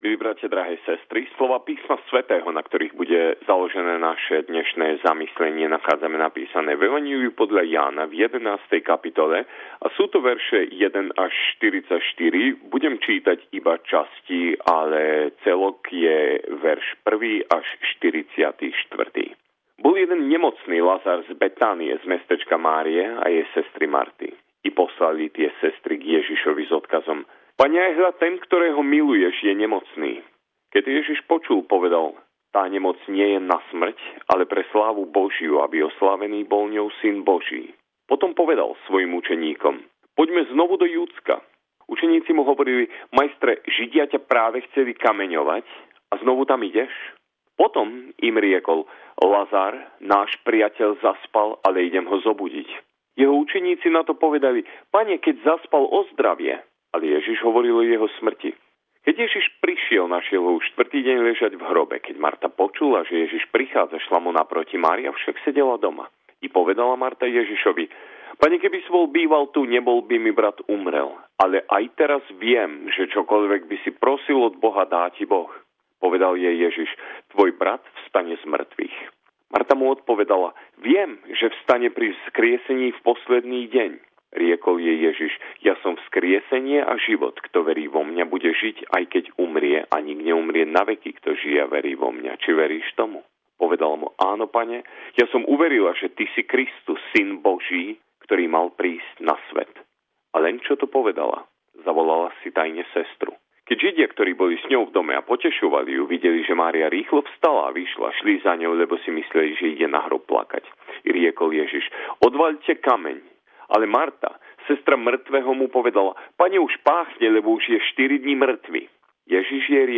Veľkonočný príhovor